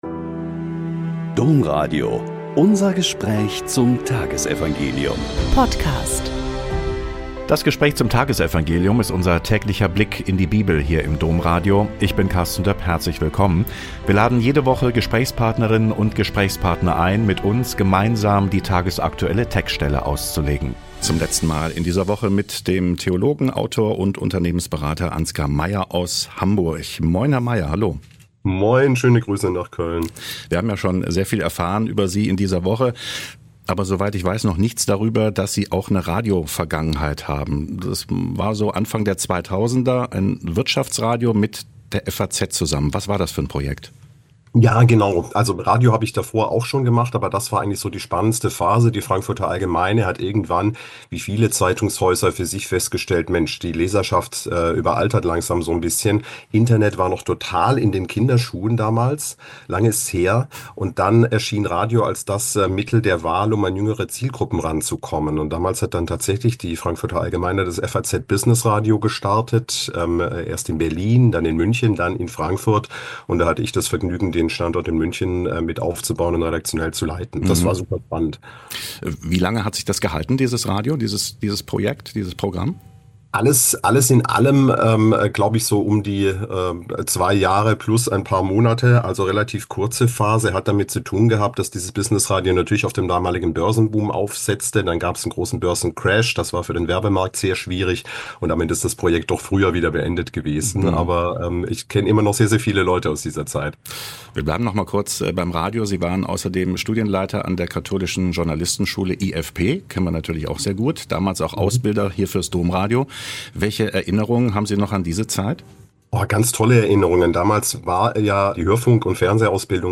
Lk 5,12-16 - Gespräch